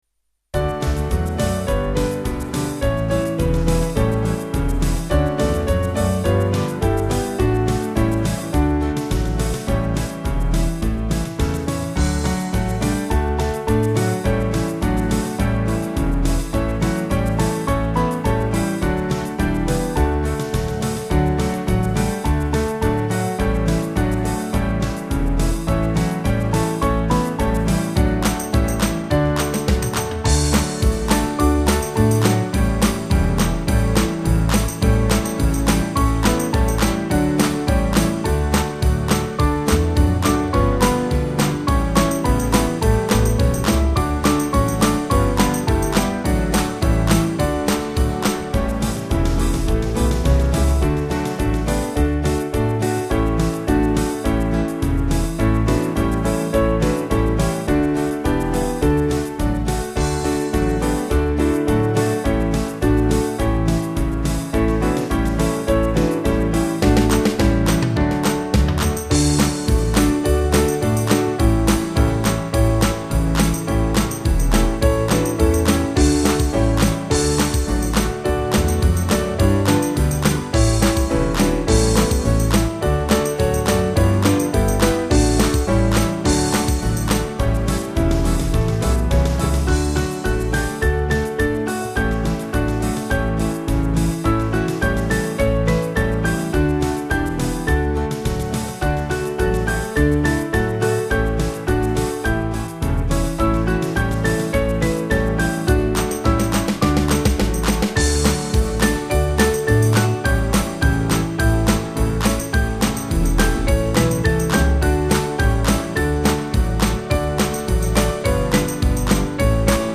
Small Band
(CM)   4/D-Eb 290.4kb